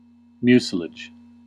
Ääntäminen
IPA: /ɣɔm/